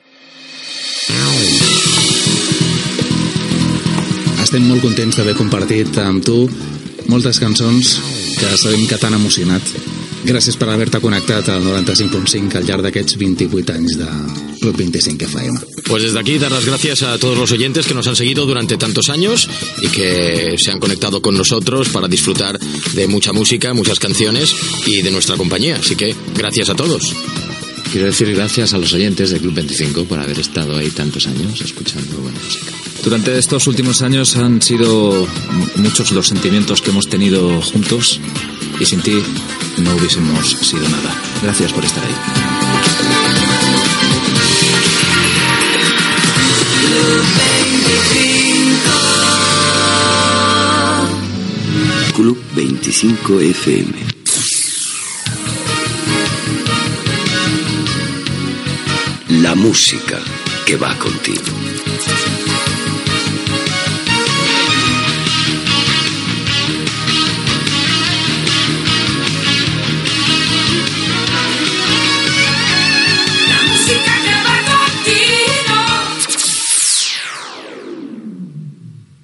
Últim minut de programaciò abans que tanqués amb diversos agraïments i l'indicatiu de l'emissora
FM